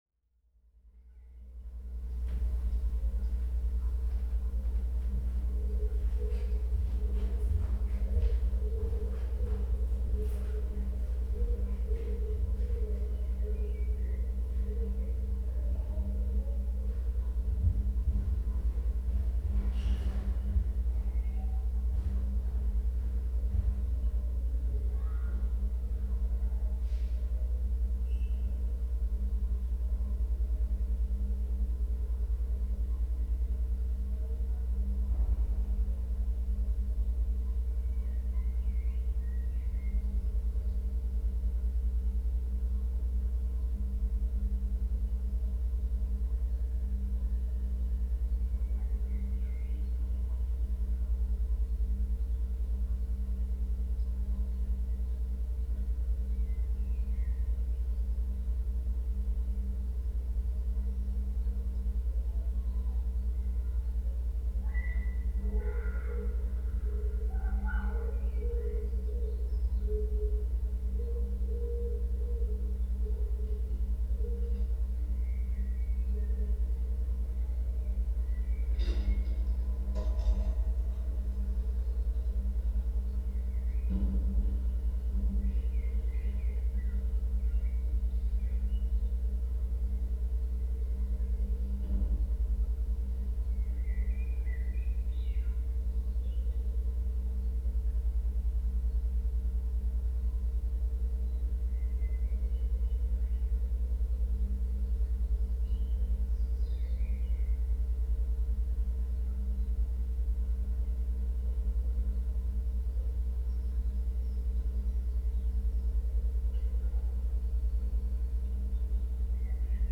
South Oxford Community Centre Lake Street Oxford
Because of the proximity between the two rooms, the effect is a slight change in the coloration of the ambient sound, a minor disturbance of the expected sonic picture. That particular room where I set the mics has two tall windows on two walls at right angle, facing South and West.
The present room configuration is a ramshackle adaptation where ceilings intersect with windows, with lots of loft space given to pigeons. https